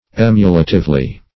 \Em"u*la*tive*ly\